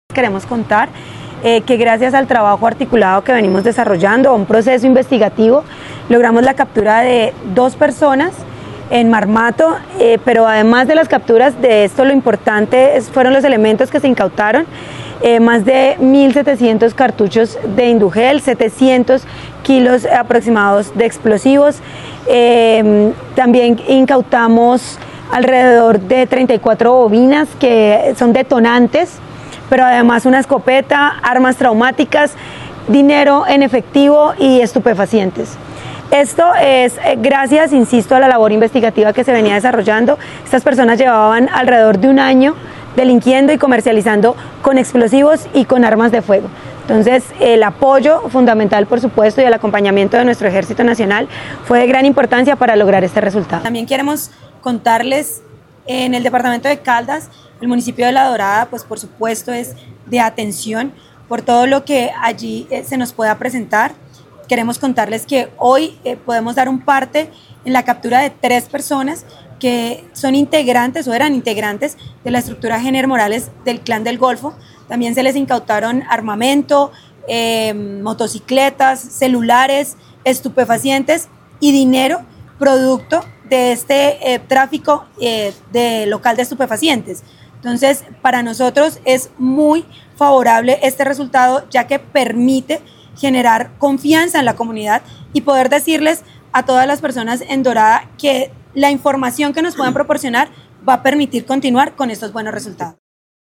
Coronel Rocío Milena Melo Puerto, comandante del Departamento de Policía Caldas
Coronel-Rocio-Milena-Melo-Puerto-comandante-del-Departamento-de-Policia-Caldas.mp3